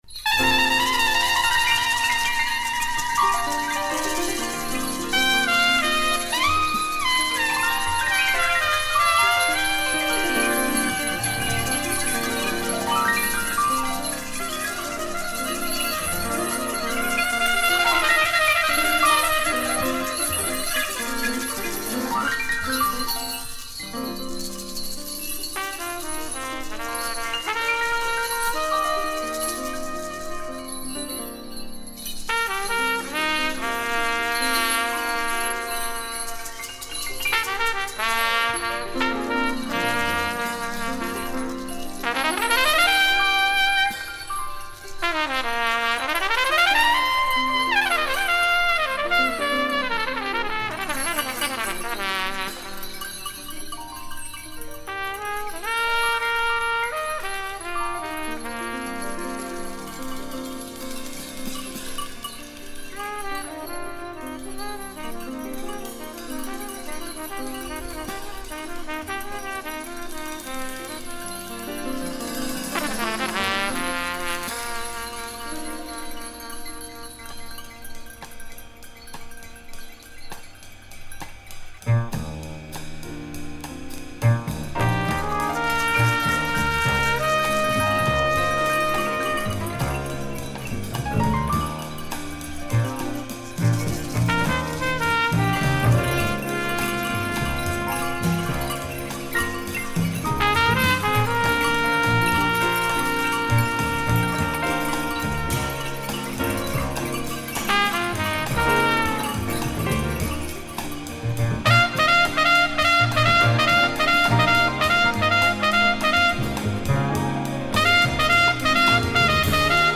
Beautiful / deep / spiritual Japanese jazz.